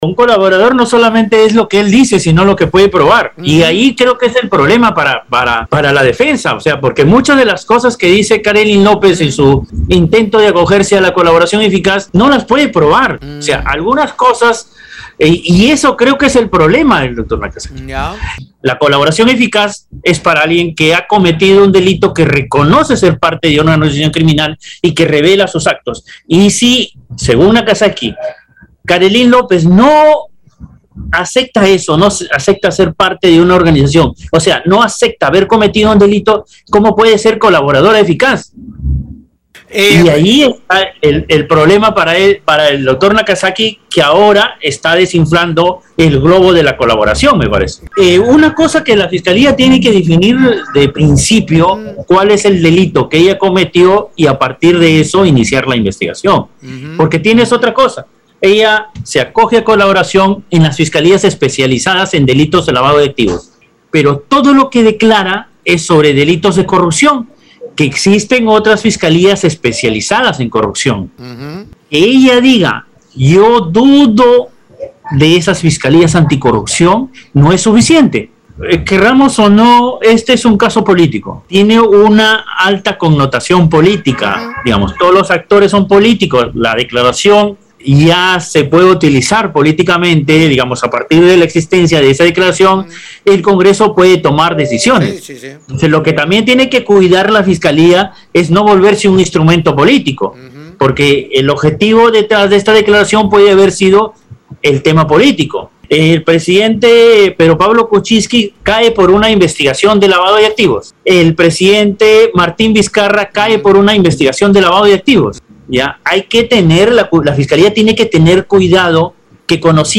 PERIODISTA-LA-REPUBLICA.mp3